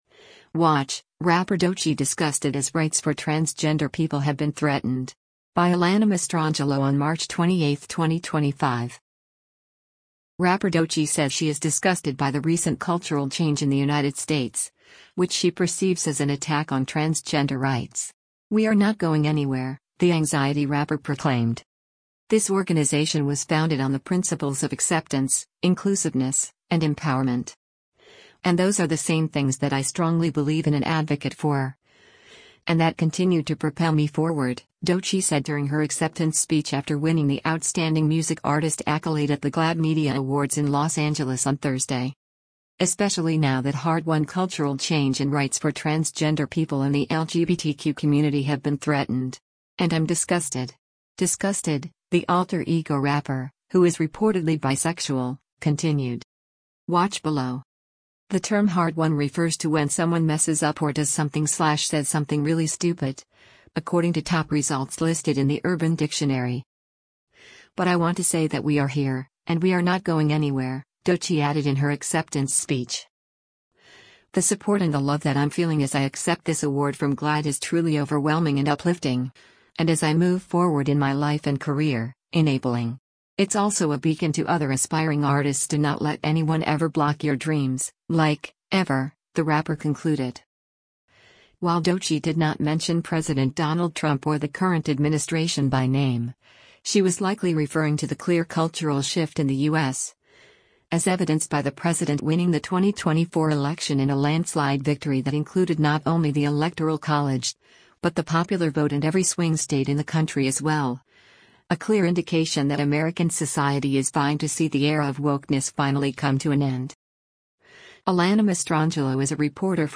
“This organization was founded on the principles of acceptance, inclusiveness, and empowerment. And those are the same things that I strongly believe in an advocate for, and that continue to propel me forward,” Doechii said during her acceptance speech after winning the Outstanding Music Artist accolade at the GLAAD Media Awards in Los Angeles on Thursday.